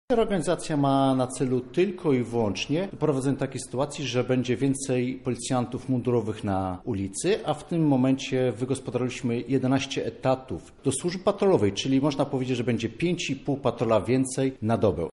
Ponadto od stycznia VIII komisariat zostanie włączony w III. Co taka reorganizacja ma na celu, tłumaczy Lubelski Komendant Wojewódzki Policji, nadinspektor Dariusz Działo.